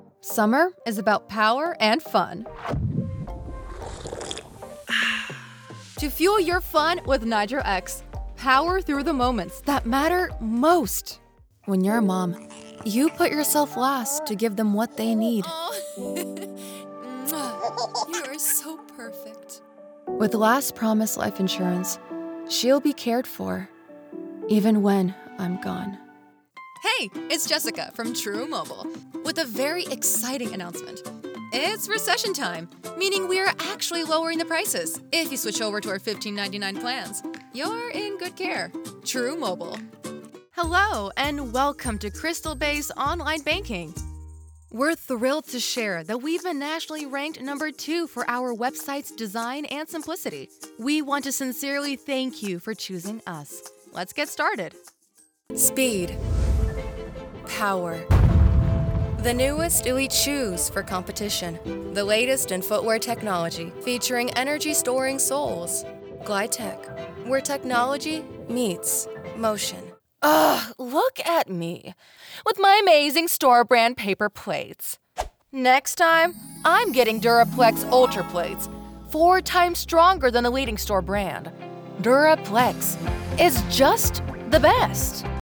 Capturing the essence of your brand with a tone that is relatable, engaging, and memorable.
Professionally Treated Vocal Booth